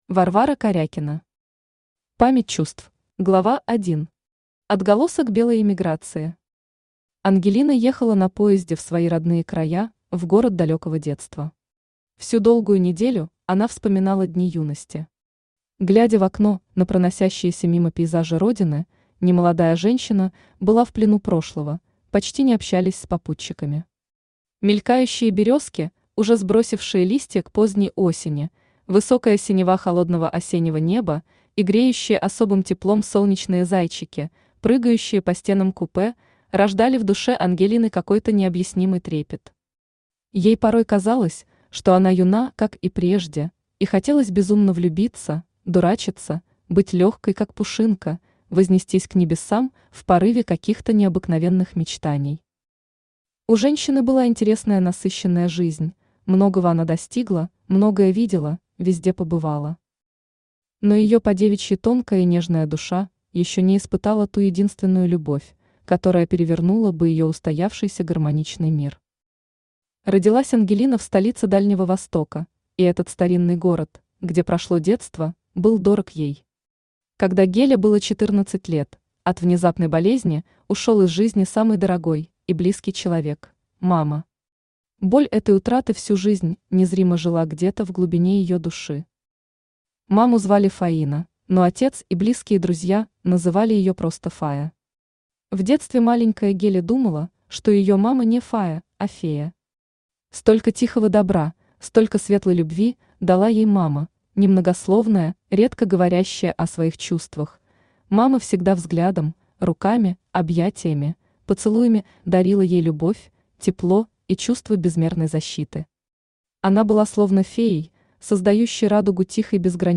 Aудиокнига Память чувств Автор Варвара Прокопьевна Корякина.